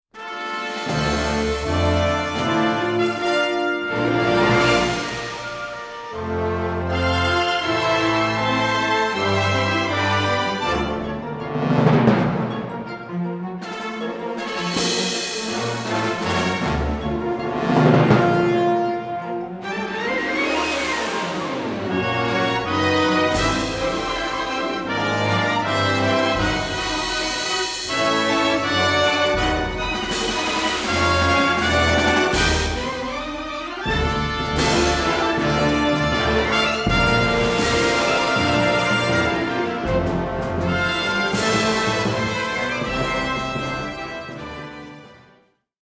robust, full-blooded music